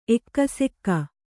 ♪ ekkasekka